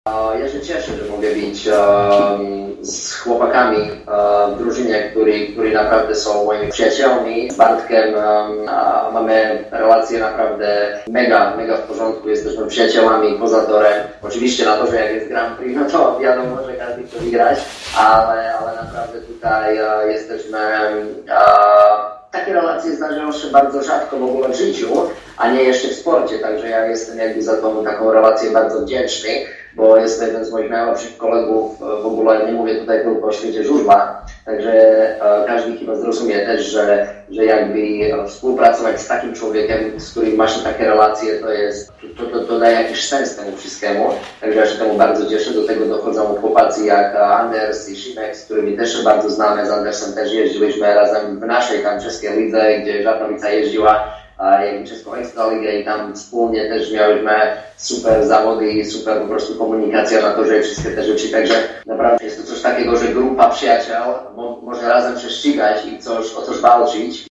Stal Gorzów zorganizowała dziś wyjątkowo długą, bo niemal 1,5 godzinną konferencję prasową.